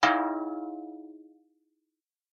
bell.mp3